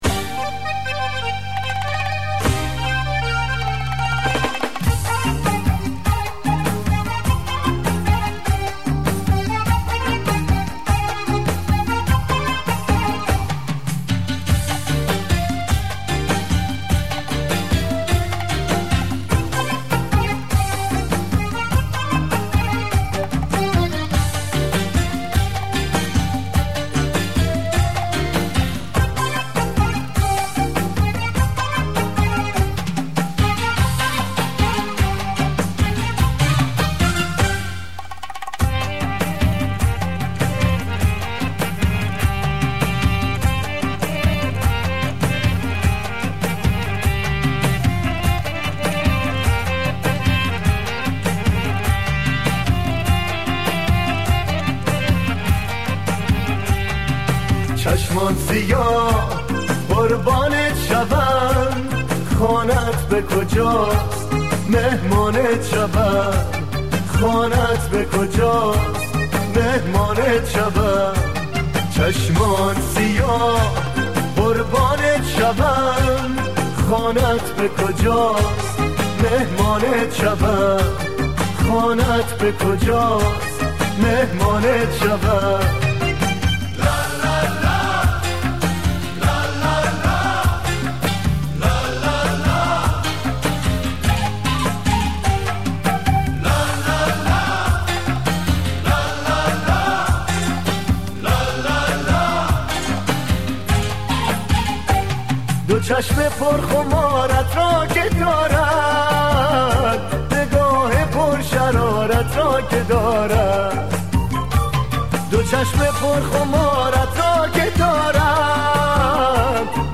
آهنگ عروسی اهنگ شاد ایرانی